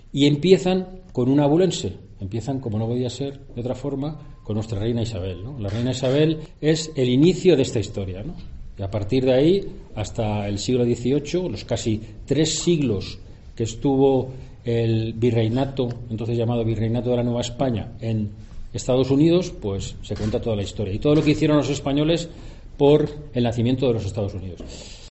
Subdelegado de Defensa, Álvaro Capella. Exposición "El legado español en los EE.UU de América"